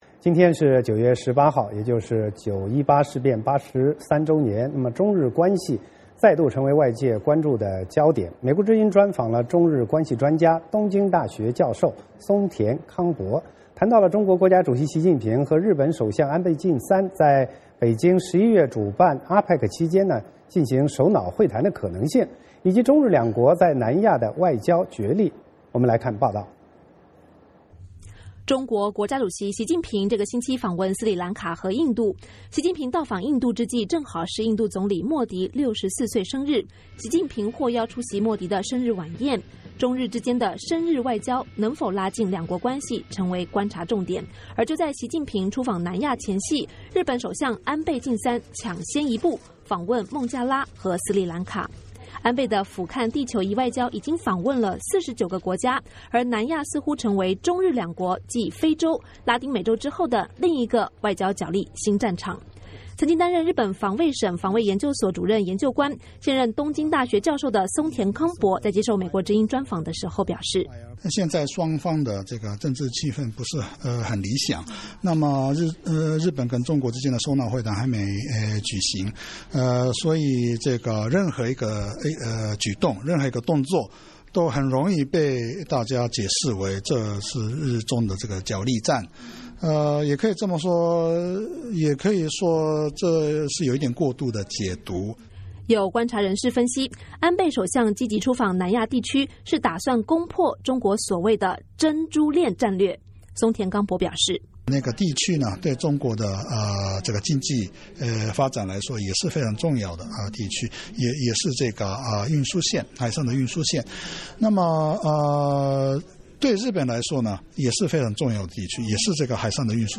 VOA卫视专访了中日关系专家